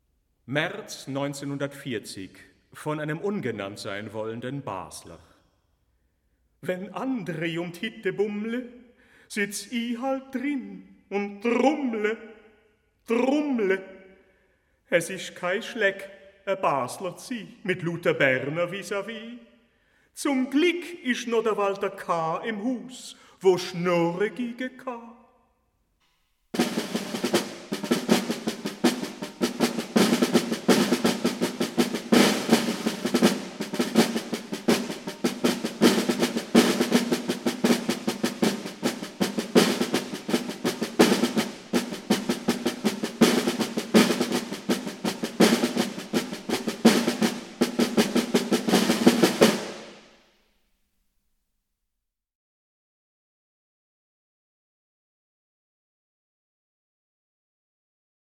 Albert Moeschinger: by an anonymus person from Basel, 1940 (8 drums)